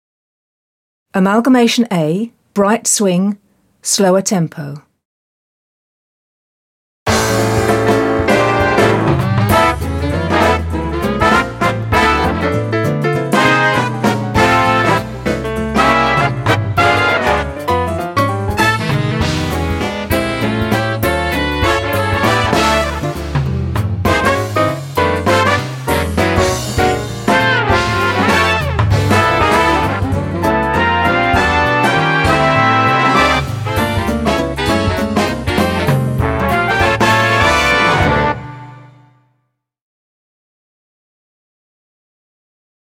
Bright Swing - slower
Rehearsal & Practice Music